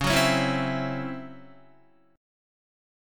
DbmM7bb5 chord